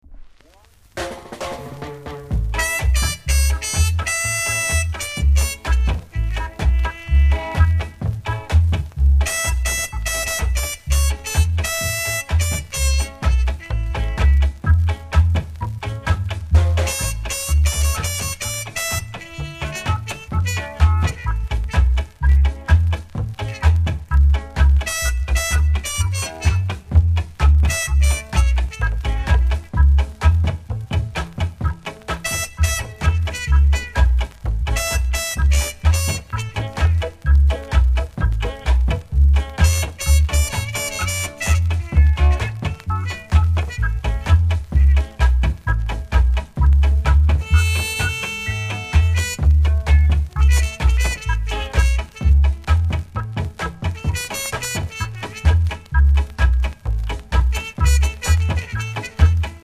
※盤は薄い擦り傷少し程度でキレイな方ですが、表面にクモリがあり全体的にややジリジリします。